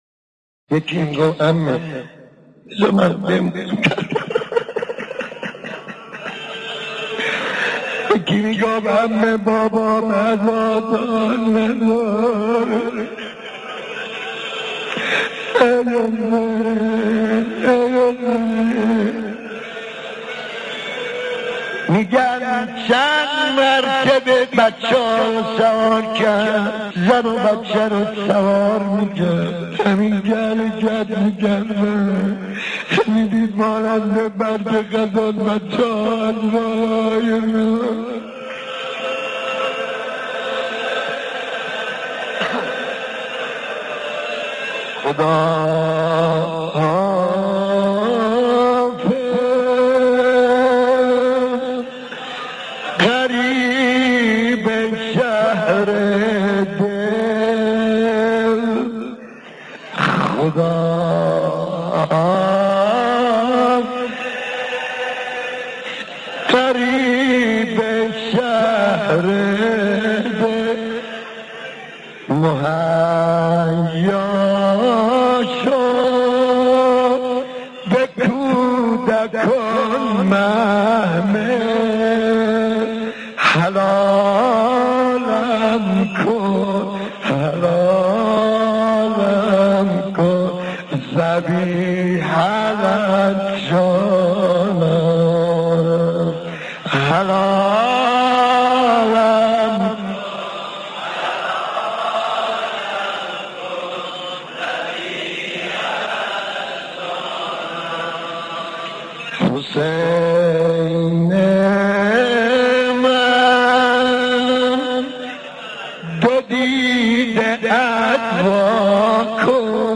روضه خوانی